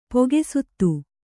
♪ poge suttu